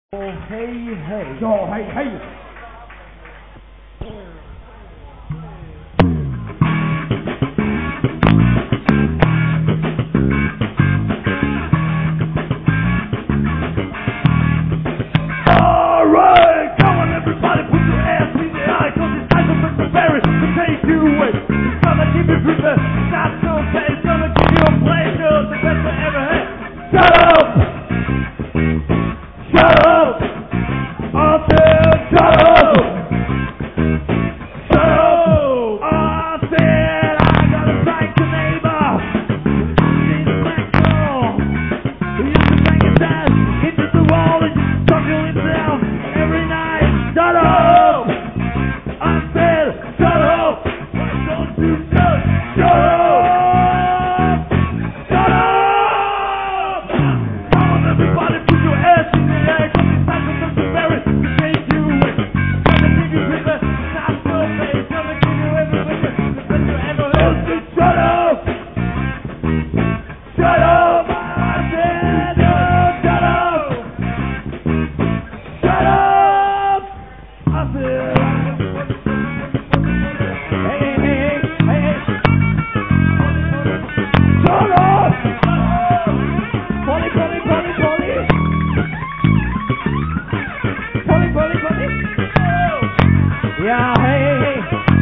Guitars, Vocals
Drums, Vocals, Bass